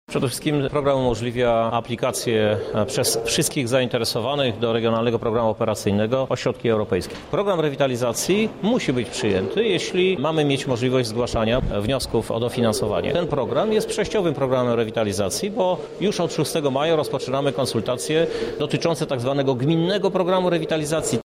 Więcej o programie mówi prezydent miasta, Krzysztof Żuk
sesja-rady-miasta.mp3